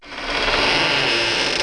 doorcreaking.wav